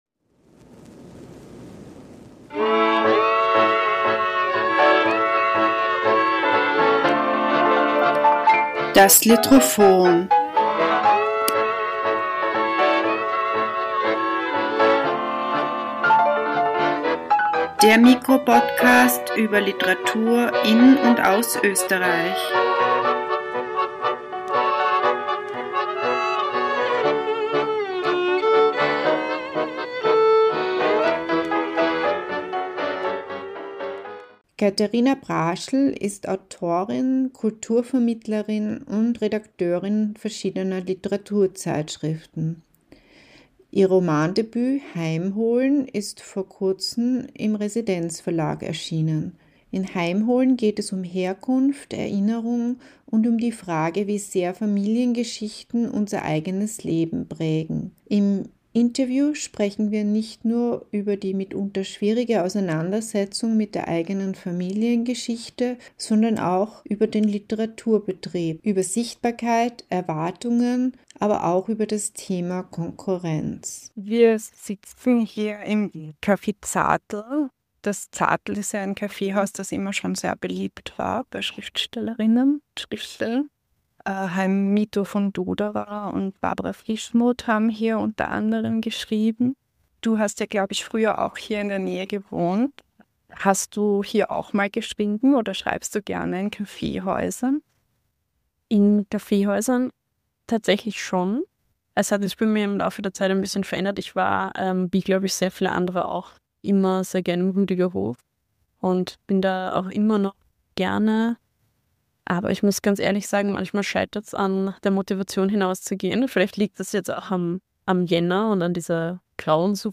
Im Podcast Gespräch erzählt die Autorin von der Schwierigkeit, sich dieser Geschichte zu stellen, Verantwortung anzuerkennen und einen eigenen Umgang mit dem Erbe der Vergangenheit zu finden. Auch der Literaturbetrieb kommt zur Sprache: Fragen von Sichtbarkeit, Erwartungen und Konkurrenz, und wie sie als Autorin damit umgeht.